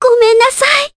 Ophelia-Vox_Dead_jp.wav